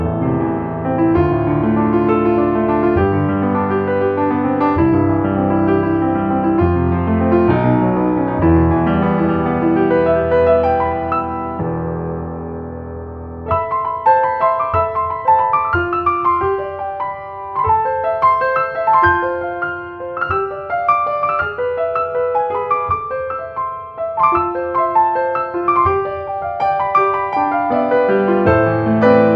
14 original, easy listening piano solos.